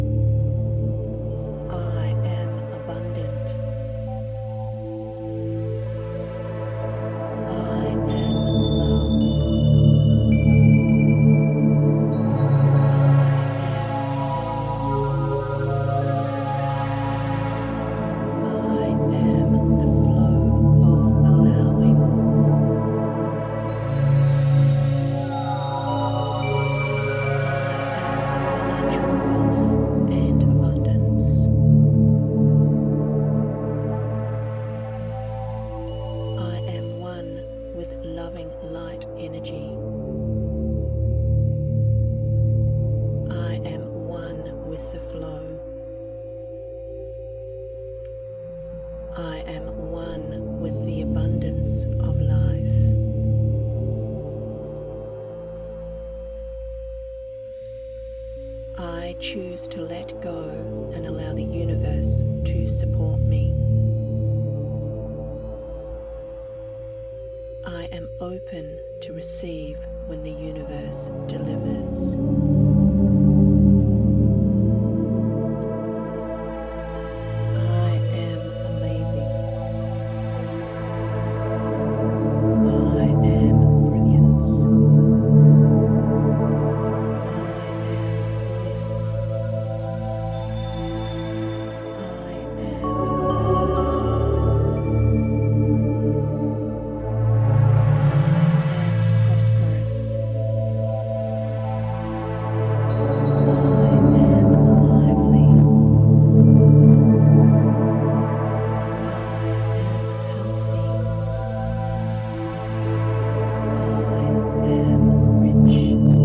The voiceover is set at a low tone as to be in the background of the music.
These audios have relaxing music along with a solfeggio binaural tone in the background. And either have affirmations in a low tone done in the “I am” statements or in the case of the creative visualisation guidance also using the “I am” statements.
We use a 528 hrz love frequency tone which resonates at a high purity vibration of love to unite us with unity conscious, balance our energy and heal.
Sample-Abundance-affirmation.mp3